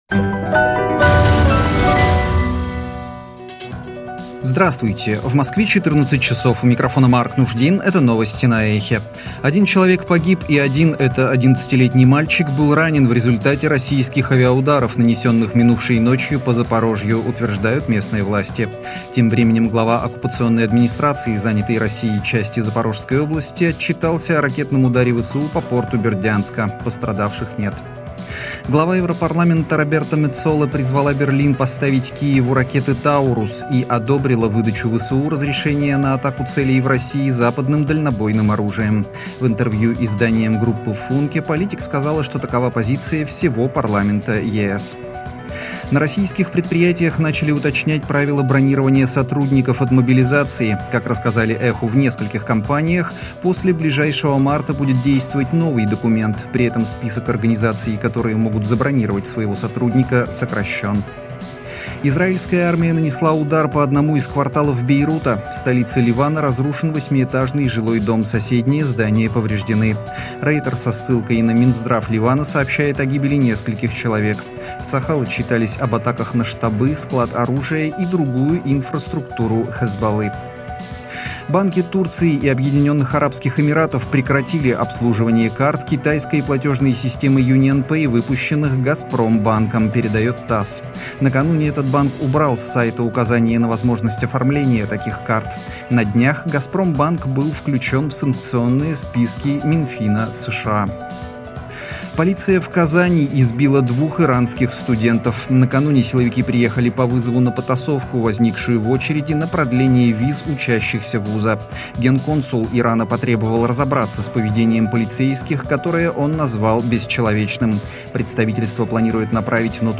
Свежий выпуск новостей